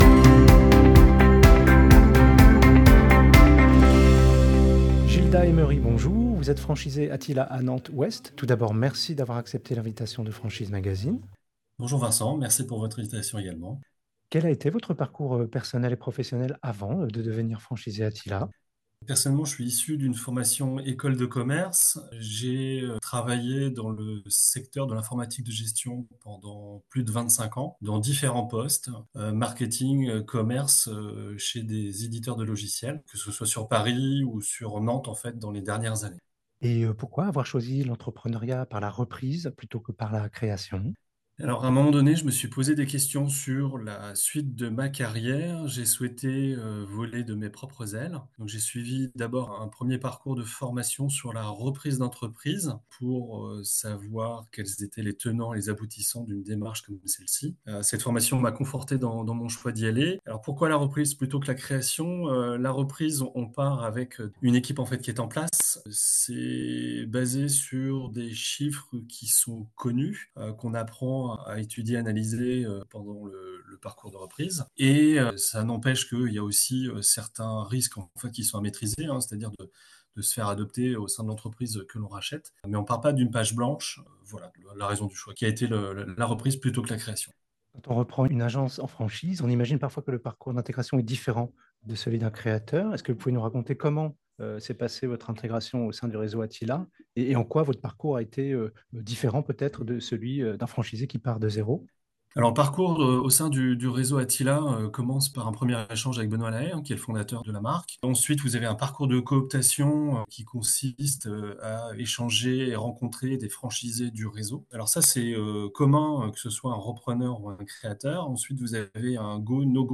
Au micro du podcast Franchise Magazine : la Franchise Attila - Écoutez l'interview